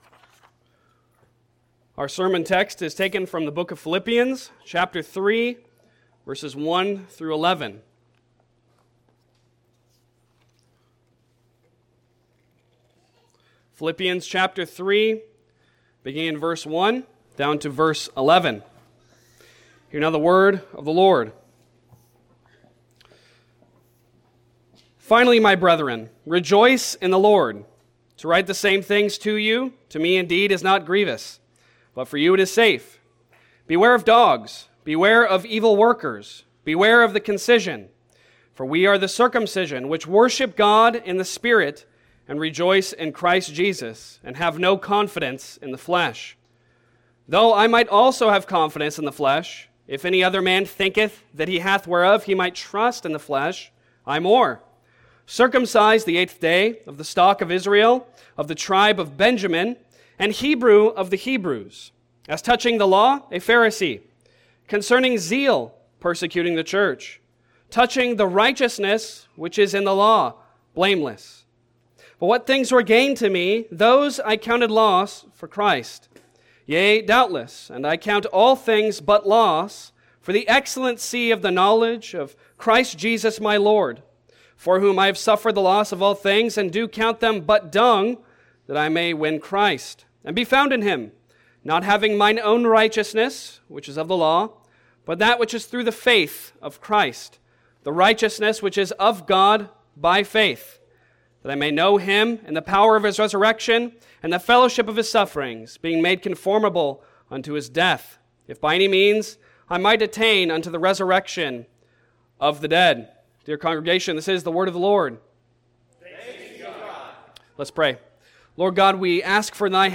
5 Solas Passage: Philippians 3:1-11 Service Type: Sunday Sermon Download Files Bulletin « Sola Gratia